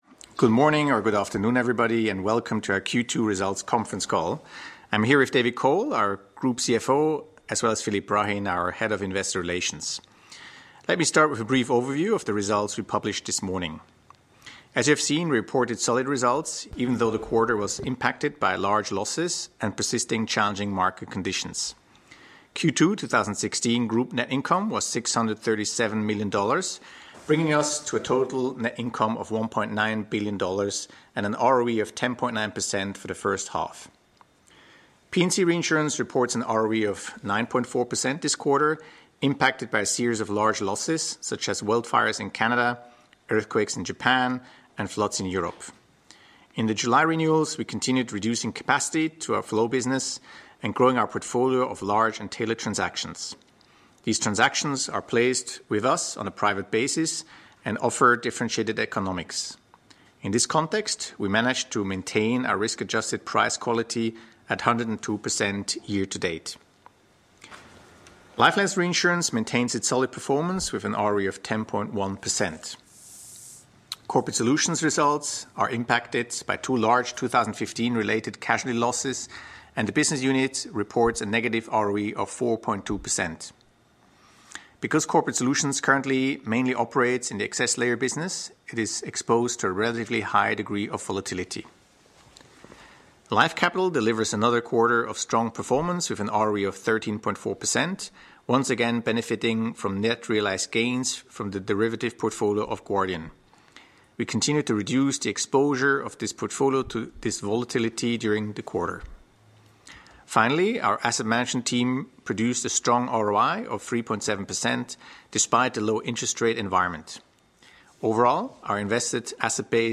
Analysts Conference call recording
2016_q2_qa_audio.mp3